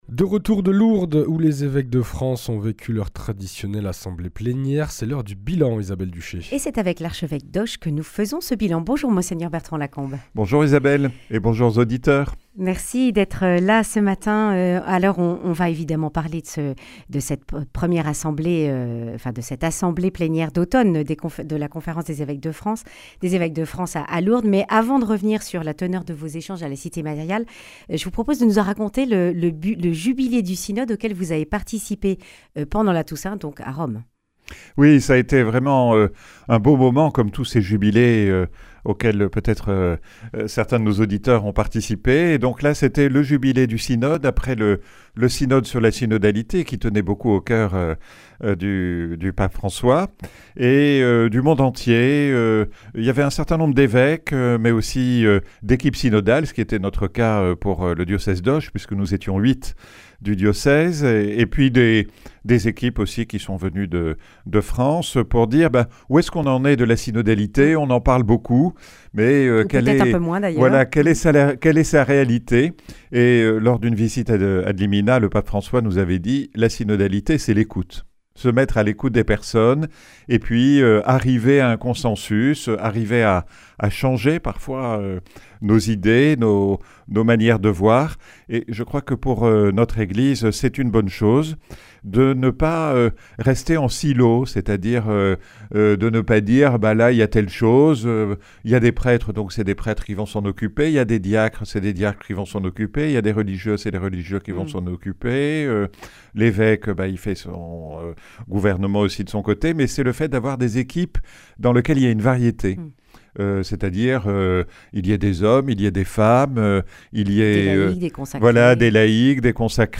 Les évêques ont vécu leur traditionnelle assemblée d’automne à Lourdes du 4 au 9 novembre. Monseigneur Bertrand Lacombe, archevêque d’Auch, en dresse le bilan. Attention particulière à l’Enseignement catholique, poursuite de la prévention et de la lutte contre les abus, regards sur le monde, etc.
Le grand entretien